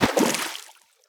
Water_splash_big_4.ogg